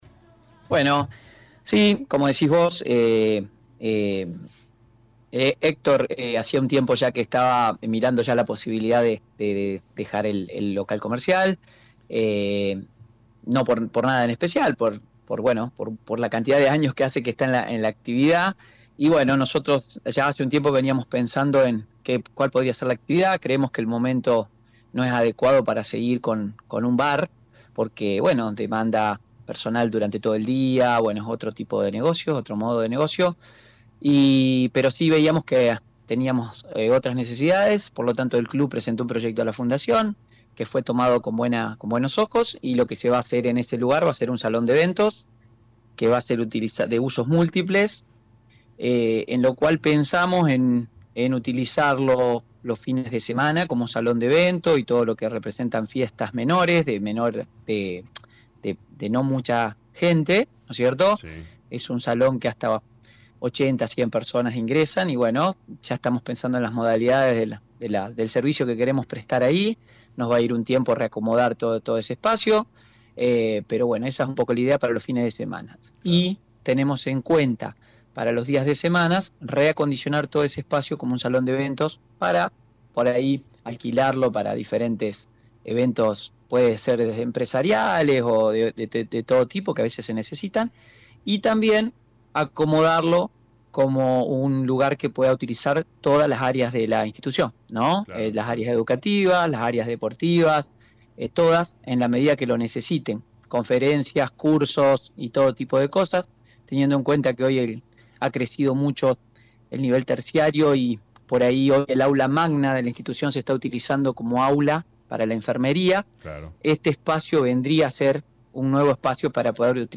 En diálogo con LA RADIO 102.9 FM